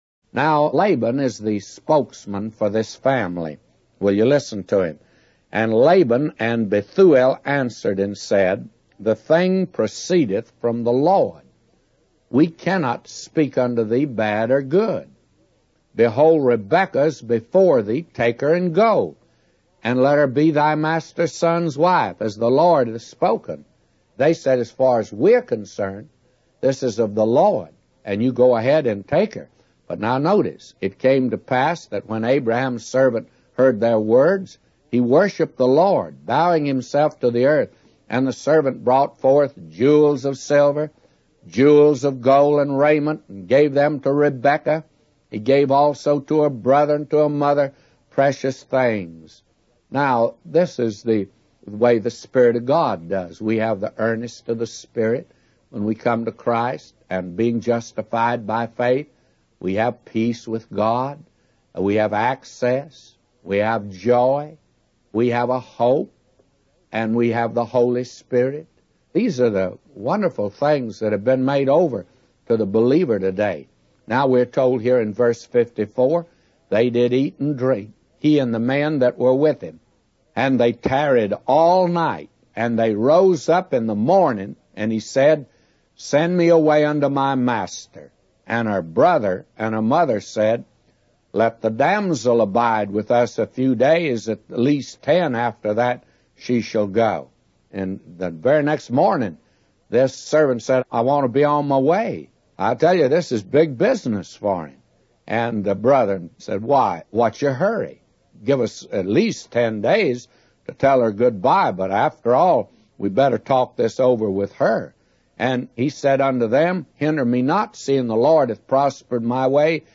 In this sermon, the speaker focuses on the story of Abraham's servant finding a wife for Isaac.